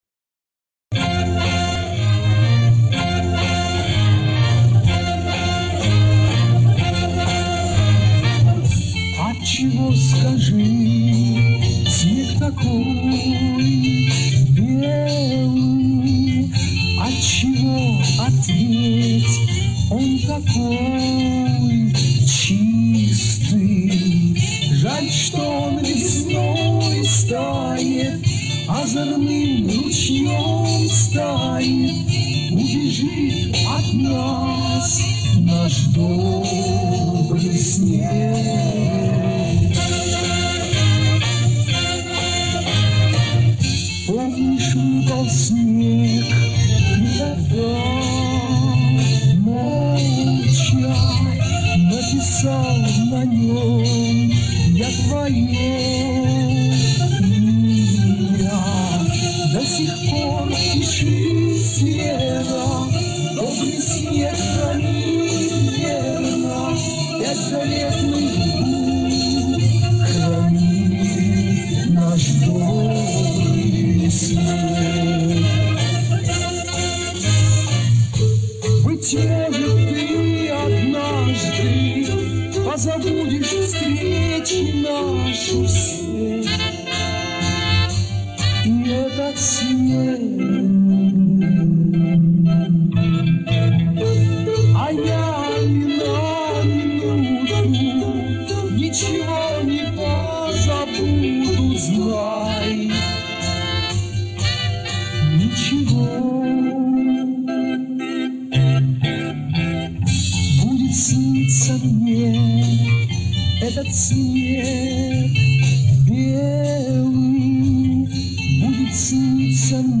Убраны провалы.